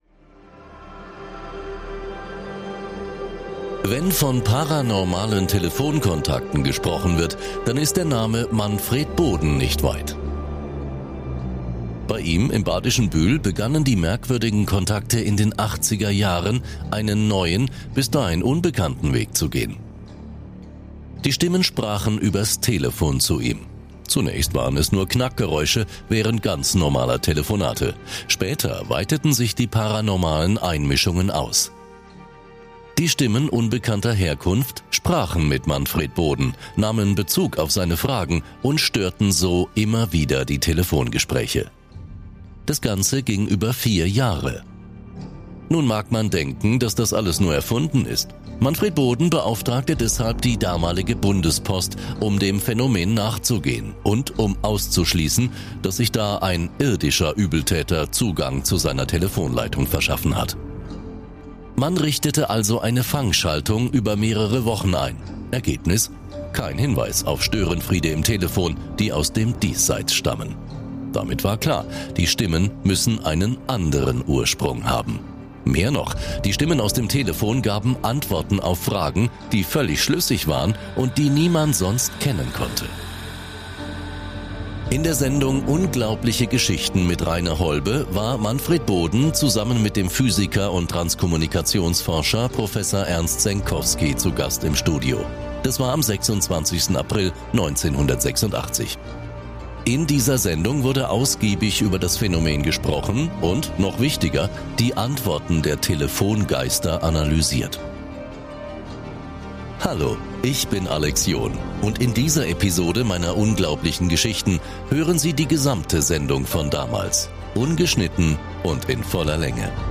Diesmal hören Sie die gesamte Sendung, die seinerzeit im Radio gesendet wurde. Ungekürzt und mit allen Stimmen.